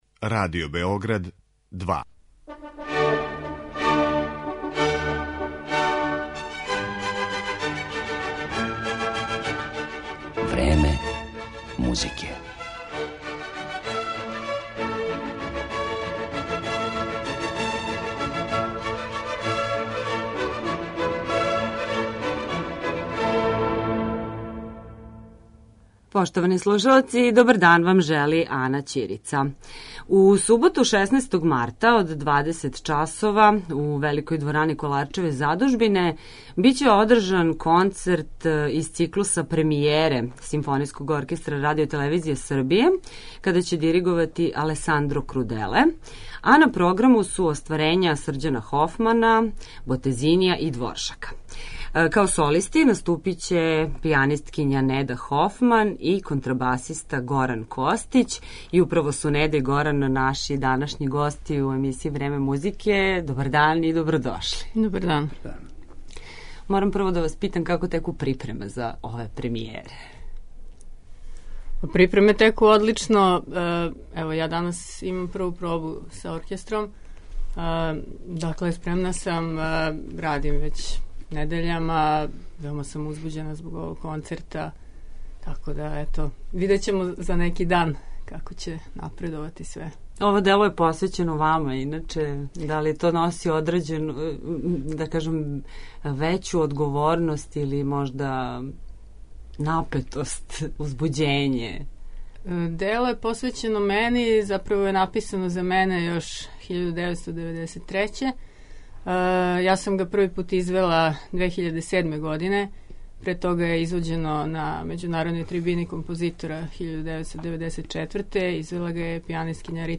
Гости емисије су пијанисткиња